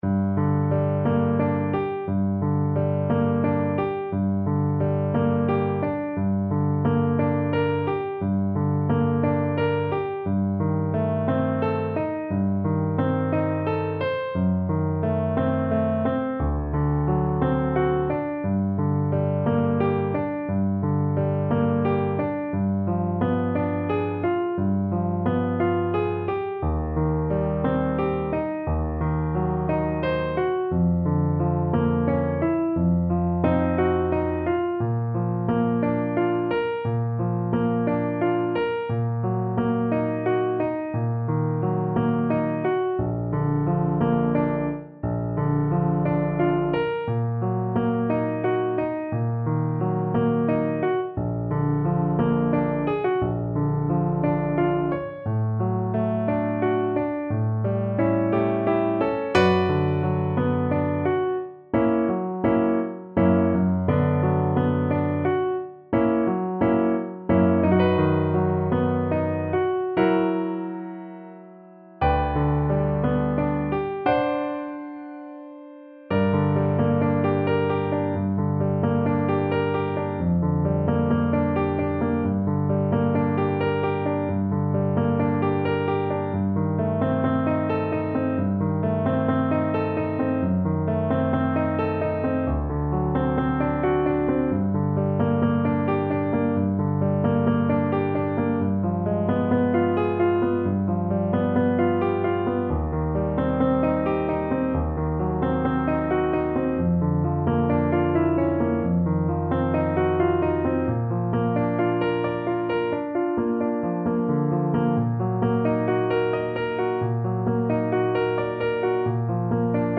Play (or use space bar on your keyboard) Pause Music Playalong - Piano Accompaniment Playalong Band Accompaniment not yet available reset tempo print settings full screen
G minor (Sounding Pitch) D minor (French Horn in F) (View more G minor Music for French Horn )
~ = 88 Malinconico espressivo
romance-s-169_HN_kar1.mp3